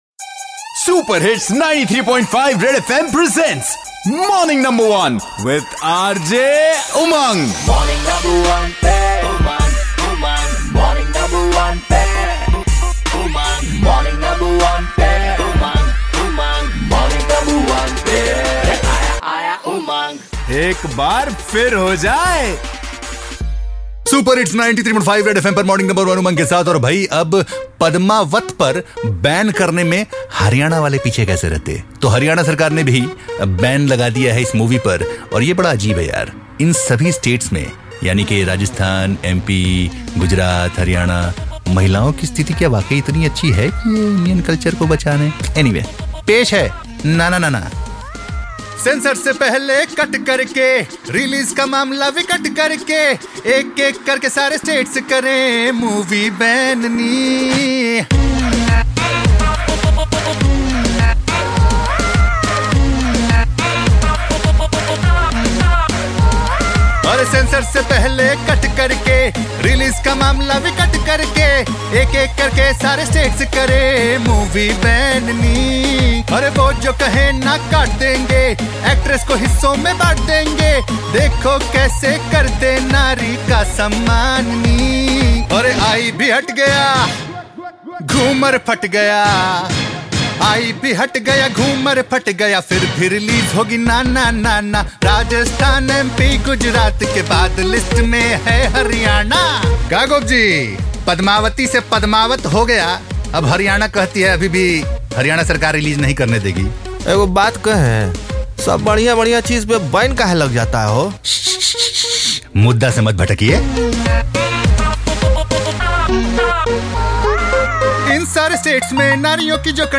Parody